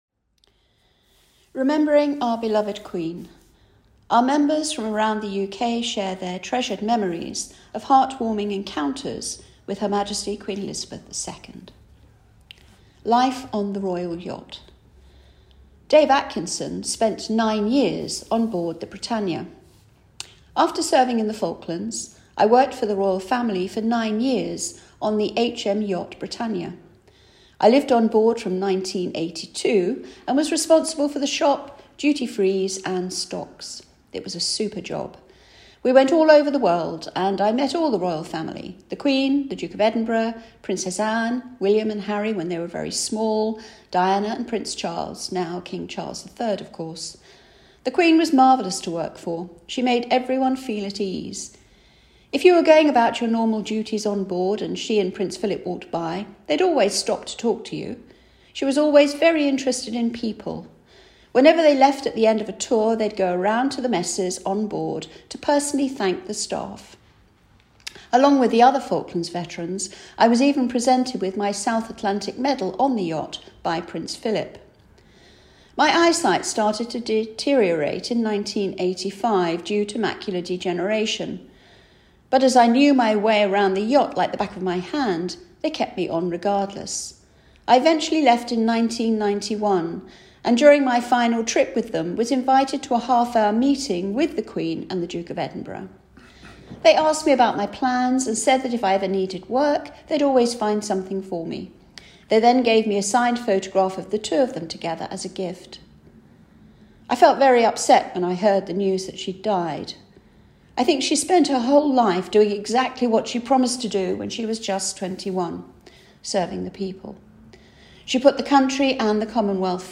Our Members from around the UK share their treasured memories of heart-warming encounters with Her Majesty Queen Elizabeth II.